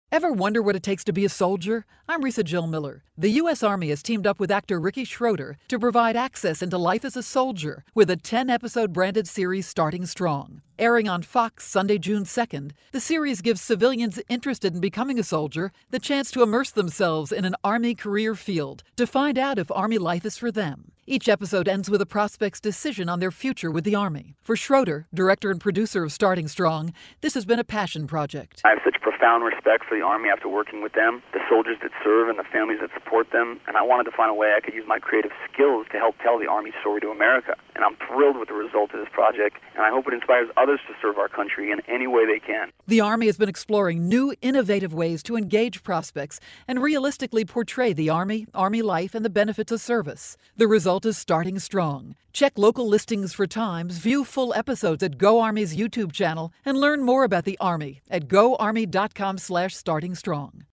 May 29, 2013Posted in: Audio News Release